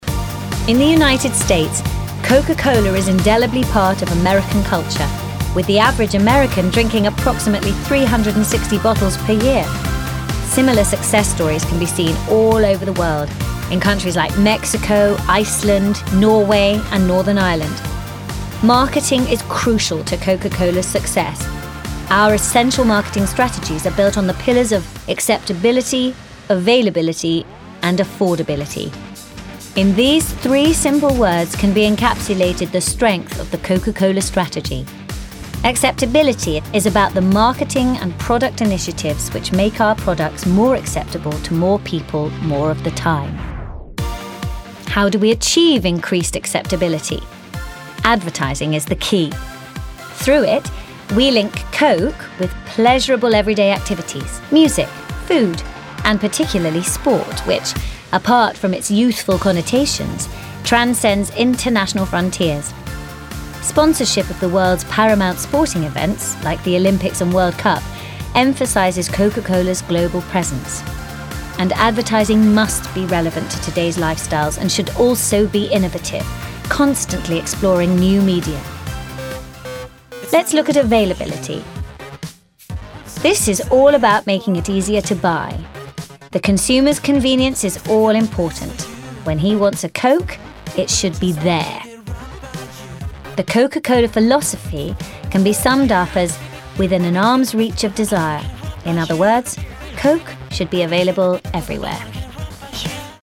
RP. Versatile characters, many accents & standard English. Bright, young, upbeat ads, also a singer.